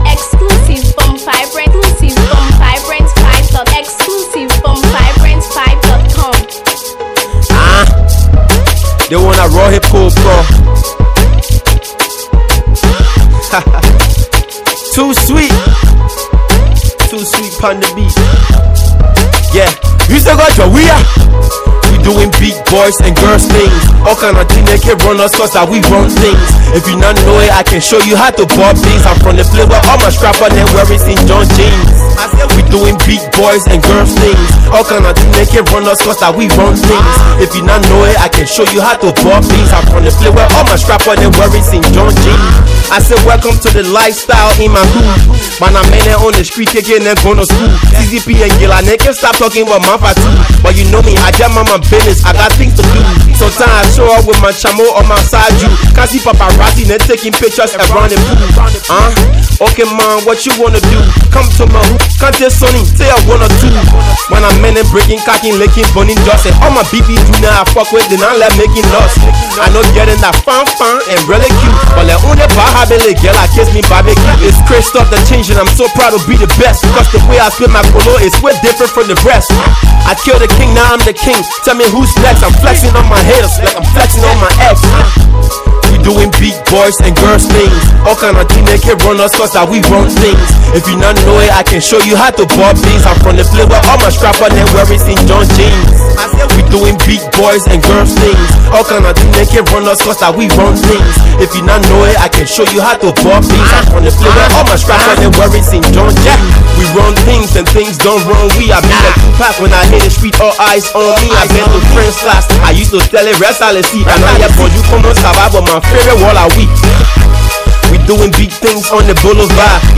and it’s a whole anthem!